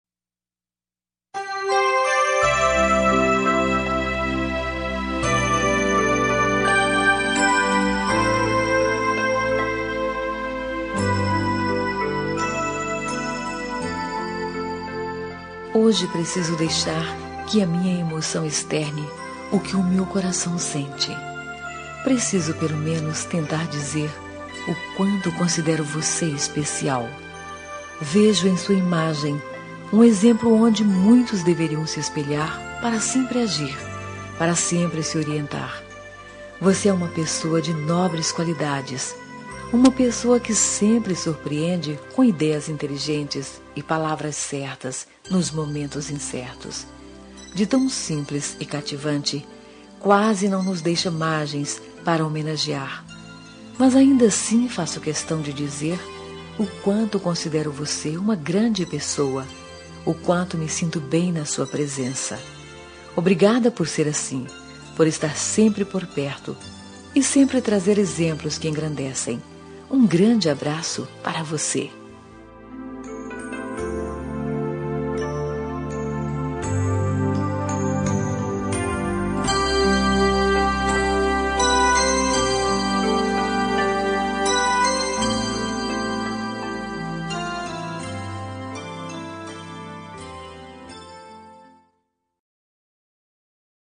Natal Pessoa Especial – Voz Feminina – Cód: 348993